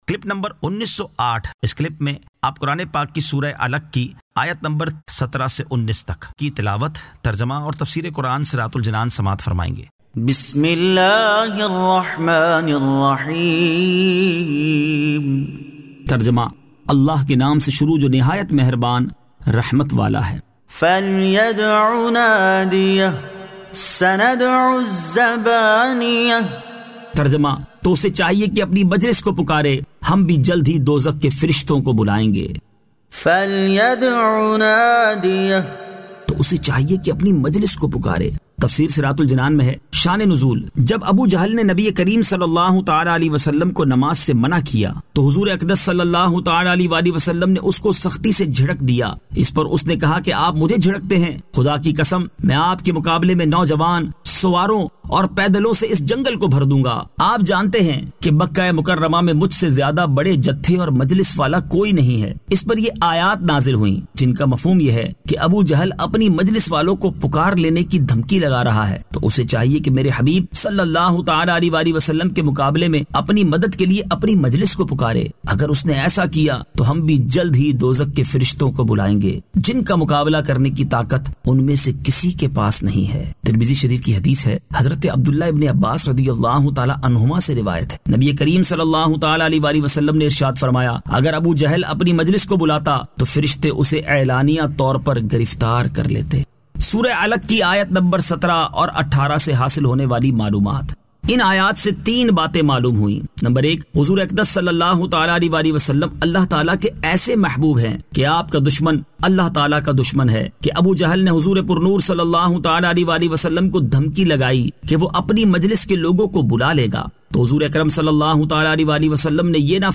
Surah Al-Alaq 17 To 19 Tilawat , Tarjama , Tafseer